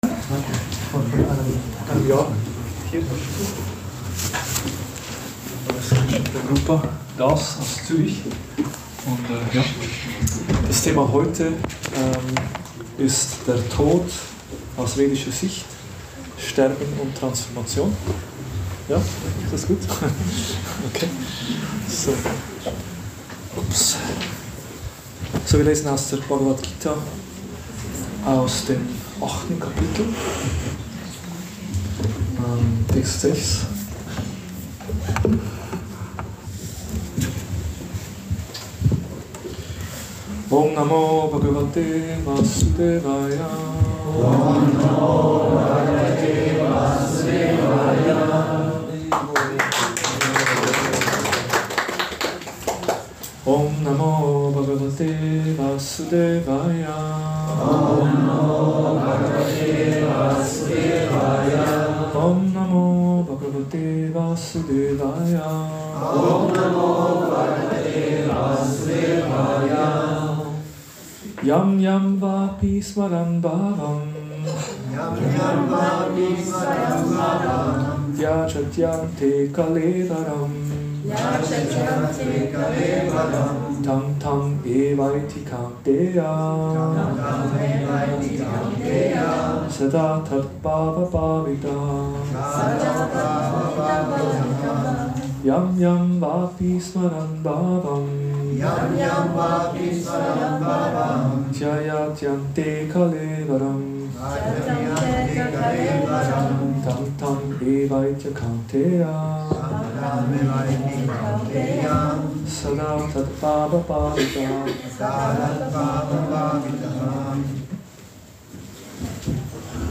Vorträge im Bhakti Yoga Zentrum Hamburg Podcast